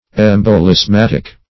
Embolismatic \Em`bo*lis*mat"ic\